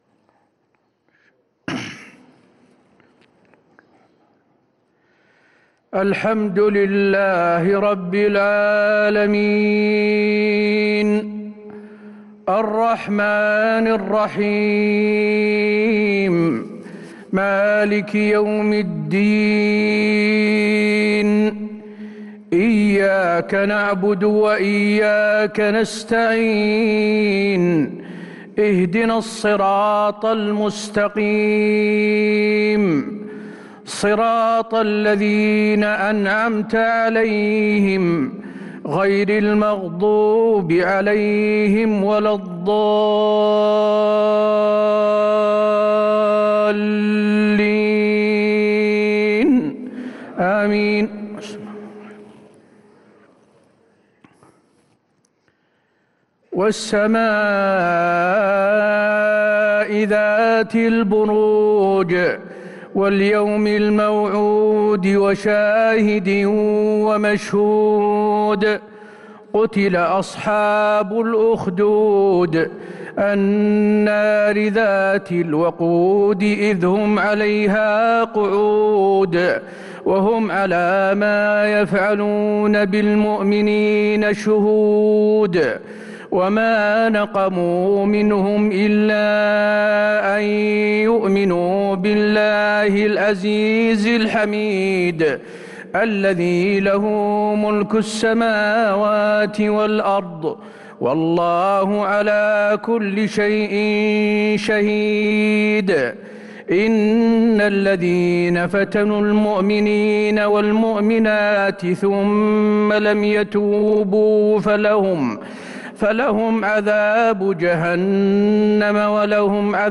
مغرب الأربعاء 1-7-1443هـ سورة البروج وأخر الأعلى | Maghrib prayer from Surah Al-Burooj & Al-Ala2-2-2022 > 1443 🕌 > الفروض - تلاوات الحرمين